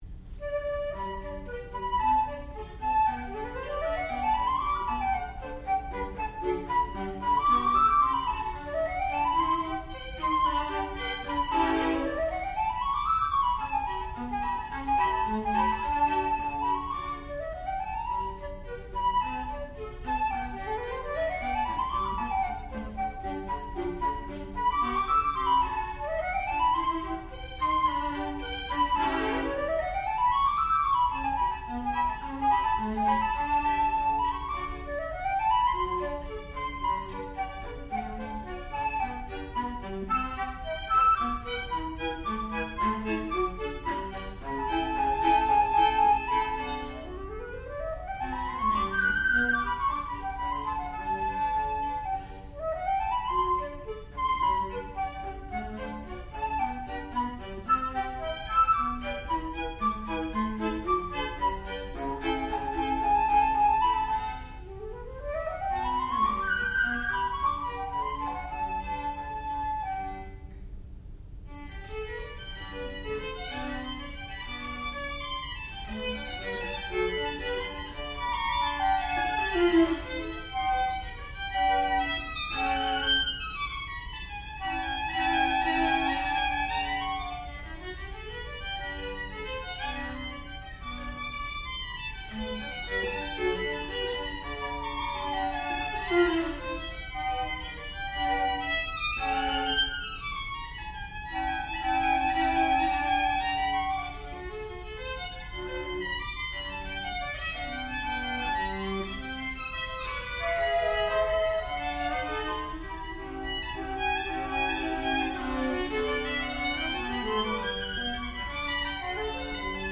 sound recording-musical
classical music
Advanced Degree Recital